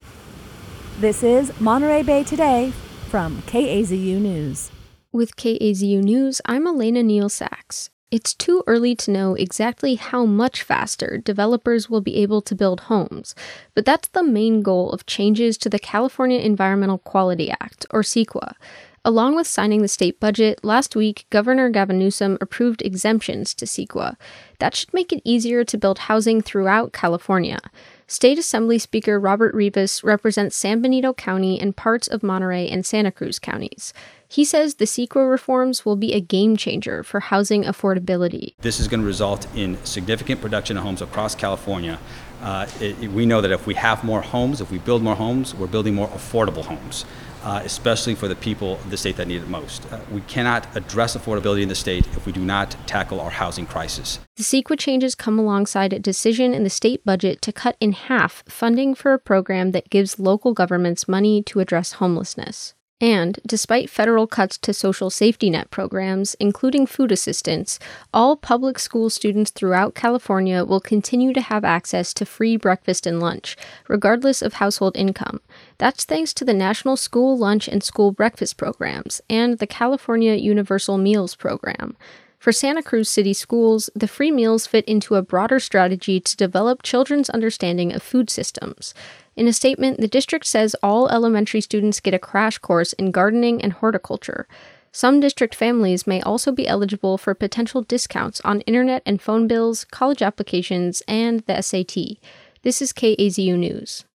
Local News Podcast from KAZU
july-7-newscast-mixdown.mp3